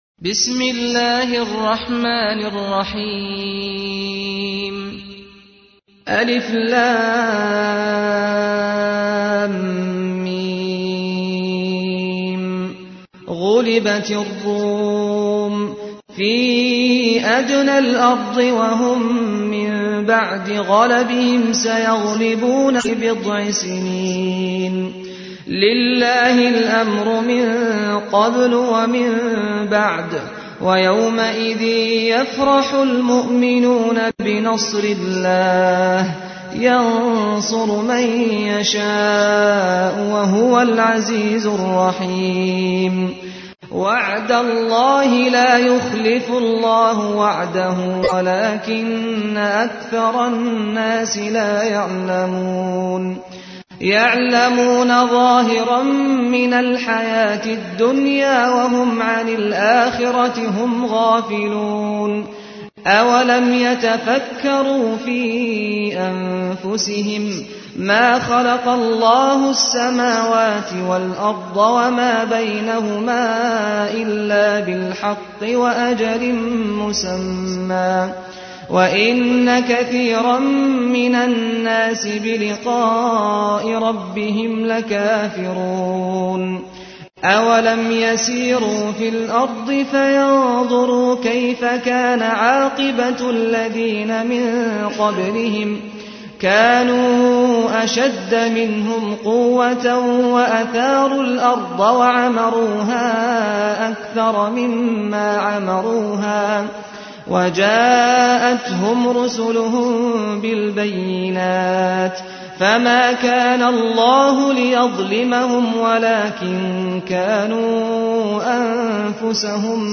تحميل : 30. سورة الروم / القارئ سعد الغامدي / القرآن الكريم / موقع يا حسين